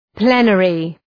Προφορά
{‘pli:nərı}